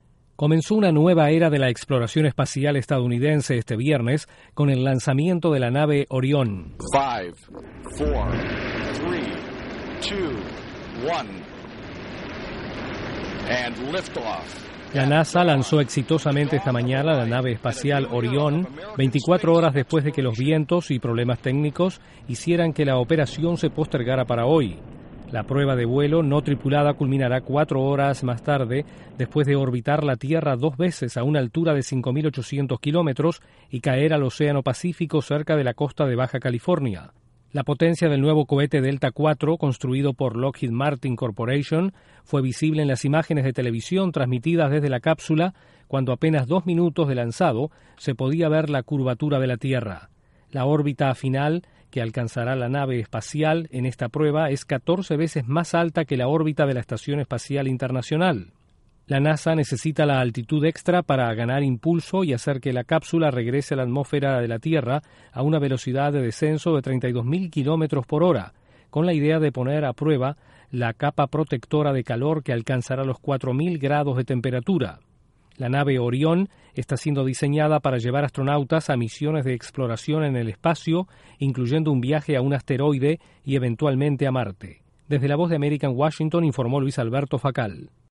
La agencia espacial de Estados Unidos lanza con éxito una nueva nave espacial. Desde la Voz de América en Washington informa